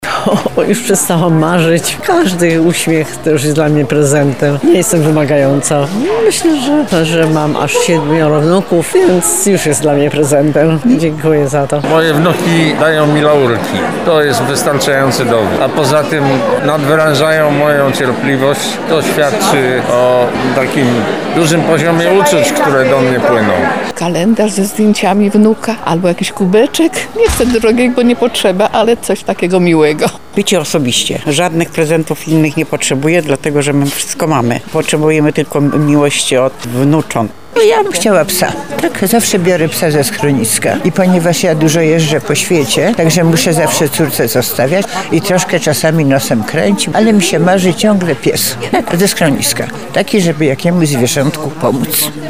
Przy okazji uroczystego Koncertu uczniów i nauczycieli Szkoły Muzycznej I i II stopnia im. Tadeusza Szeligowskiego w Lublinie zapytaliśmy seniorów i seniorki o to, jaki jest ich wymarzony prezent:
[SONDA]